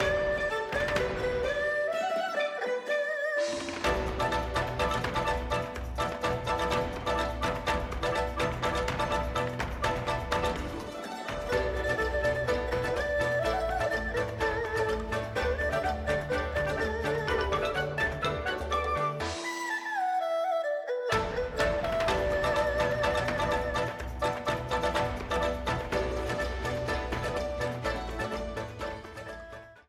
A blue streamer theme
Ripped from the game
clipped to 30 seconds and applied fade-out